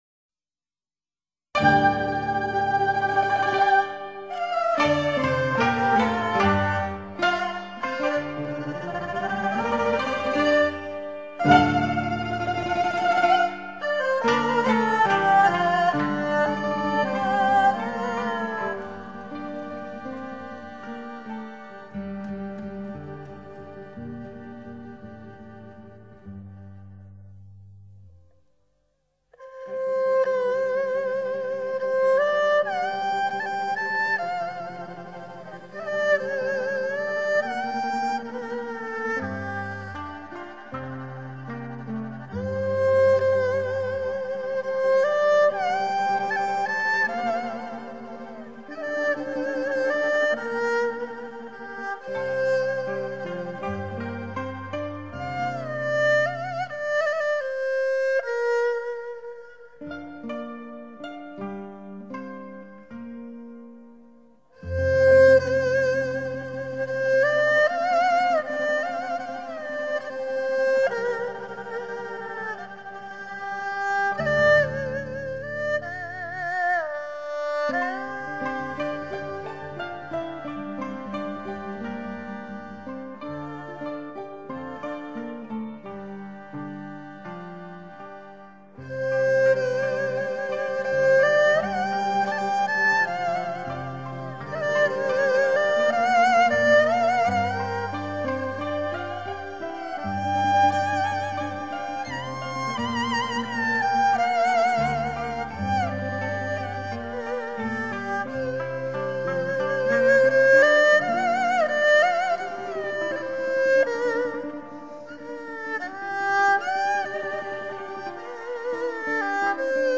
音乐类型：民乐
如是，我們聽出舒緩曲中的悠揚瀟灑，品出熱烈節奏中的柔情蜜意，熱耳而不酸心，曲短而又思長的韻味。